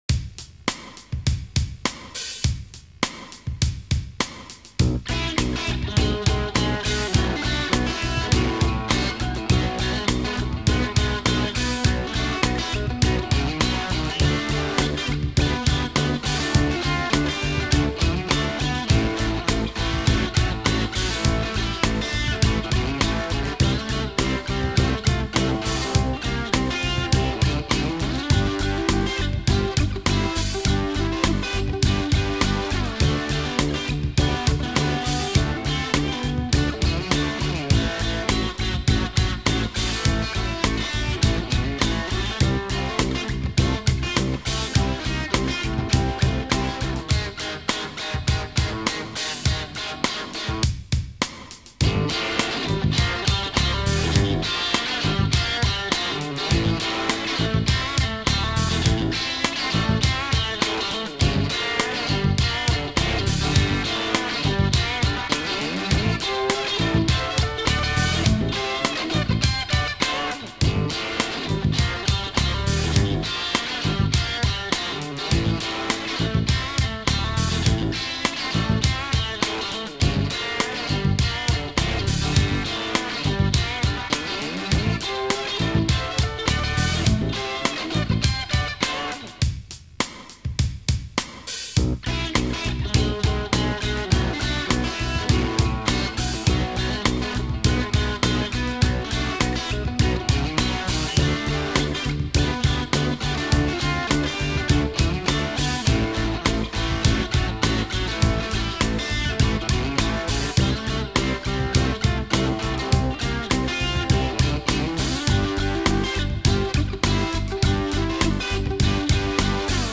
ça y'est (mais y'a pas de paroles)